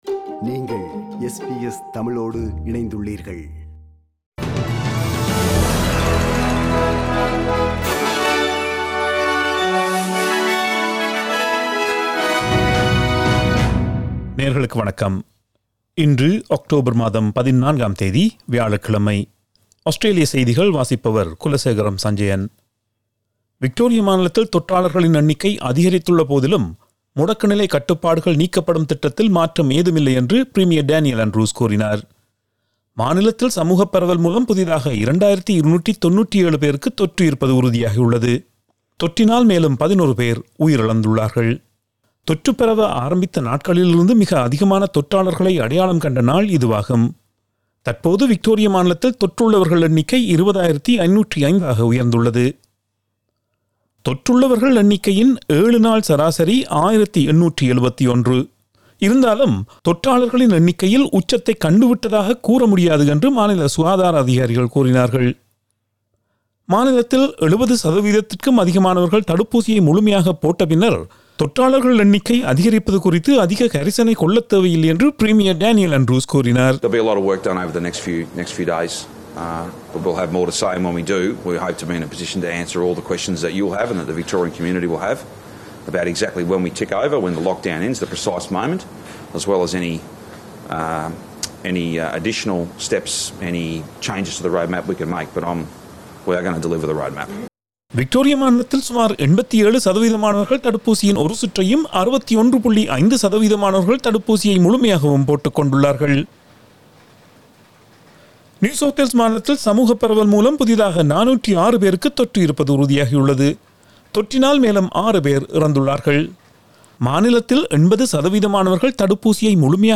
Australian news bulletin for Thursday 14 October 2021.